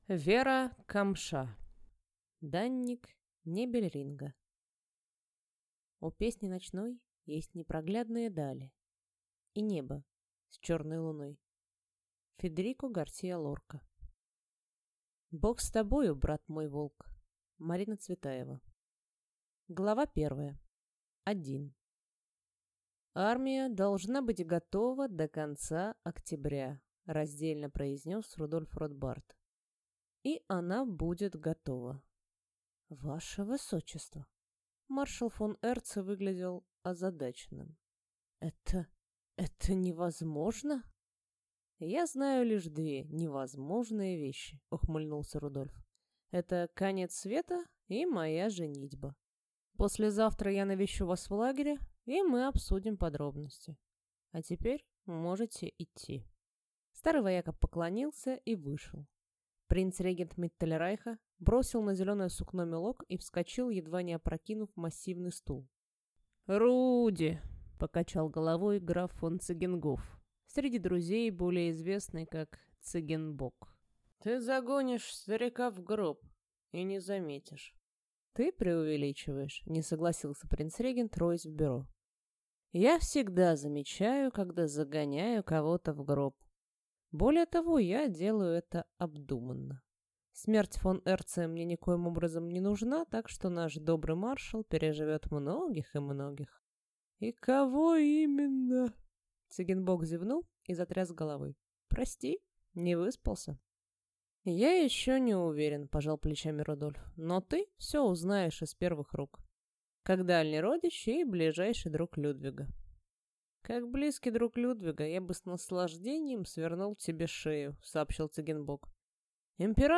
Аудиокнига Данник Небельринга | Библиотека аудиокниг